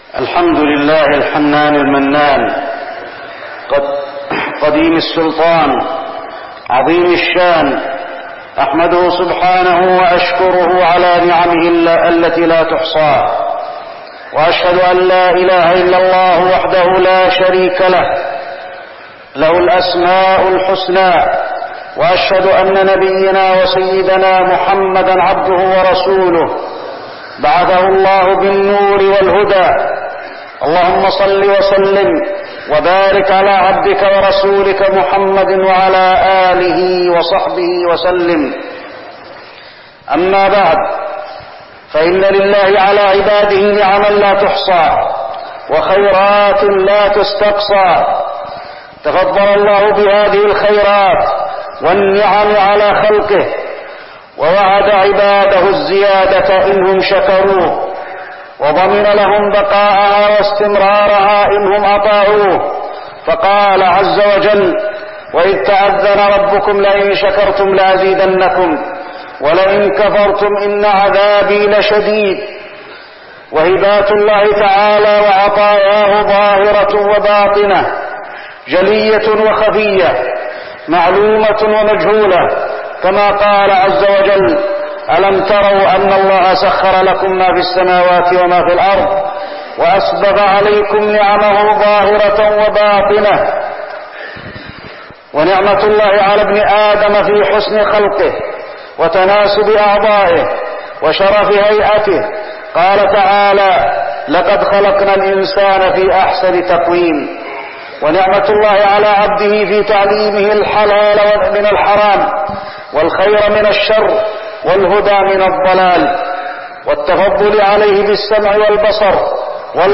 تاريخ النشر ٧ رجب ١٤١٥ هـ المكان: المسجد النبوي الشيخ: فضيلة الشيخ د. علي بن عبدالرحمن الحذيفي فضيلة الشيخ د. علي بن عبدالرحمن الحذيفي شكر الله على نعمه